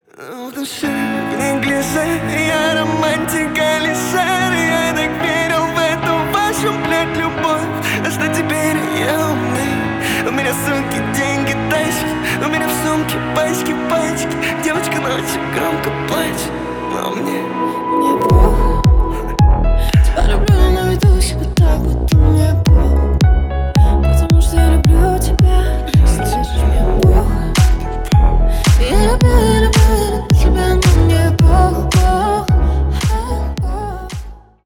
Ремикс # Поп Музыка